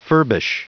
Prononciation du mot furbish en anglais (fichier audio)
Prononciation du mot : furbish